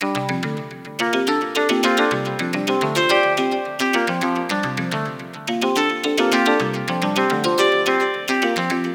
okay! so rainbow made me think of arpeggios for some reason, something about the rising and falling of notes on a scale. also, it made me think of FM synthesis because you can get some very harmonically rich sounds out of that and if rainbows had a sound I think it would be a very full sounding FM Chord.
Anyways, its not exactly an arpeggio but I came up with this sequence on my fm synth: